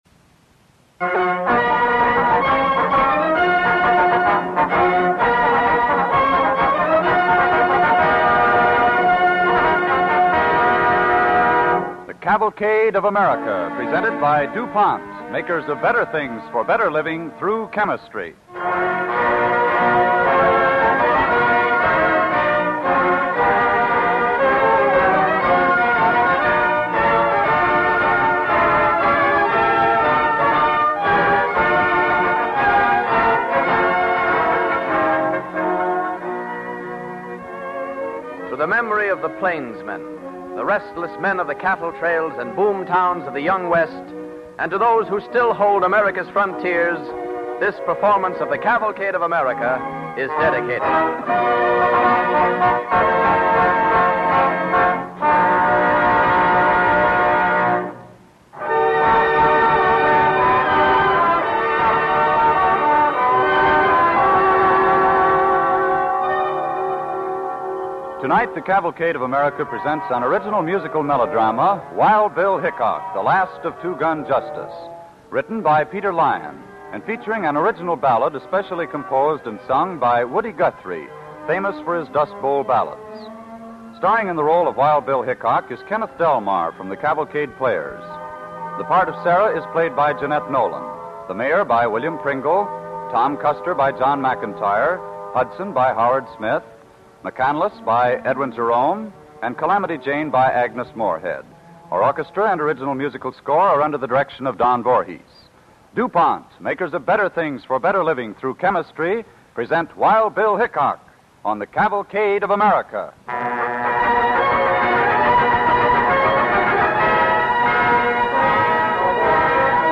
Cavalcade of America Radio Program